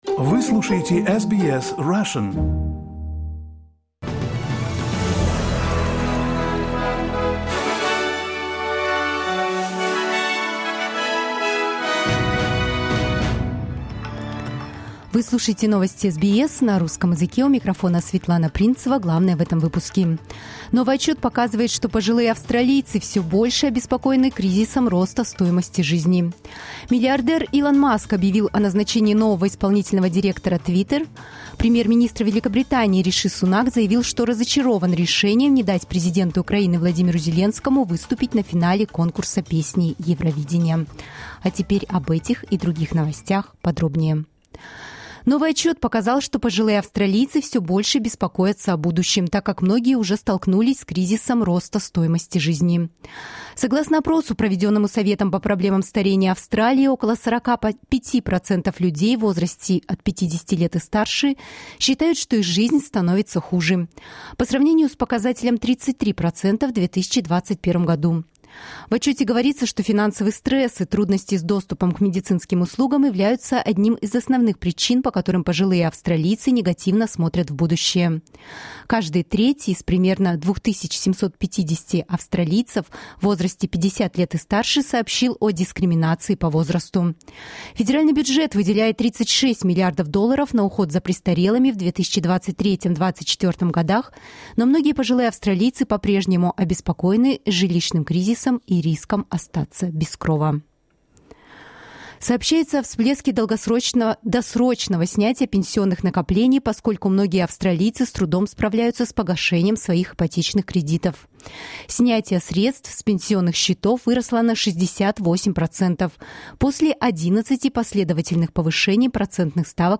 SBS news in Russian — 13.05.2023